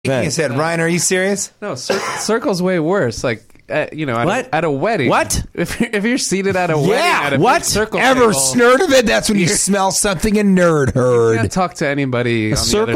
(Pronounced like 'heard') When you smell something a nerd heard.